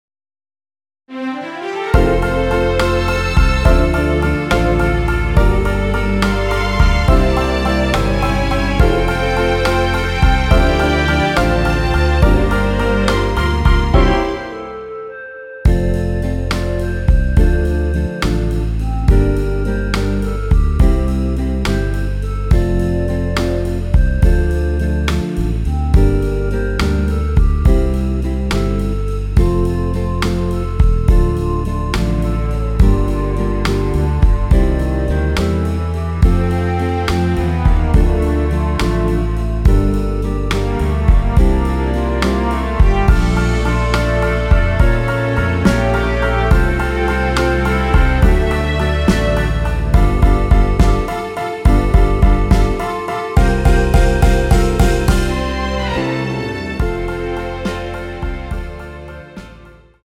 원키에서(-1)내린 멜로디 포함된 MR입니다.(미리듣기 확인)
앞부분30초, 뒷부분30초씩 편집해서 올려 드리고 있습니다.
곡명 옆 (-1)은 반음 내림, (+1)은 반음 올림 입니다.
(멜로디 MR)은 가이드 멜로디가 포함된 MR 입니다.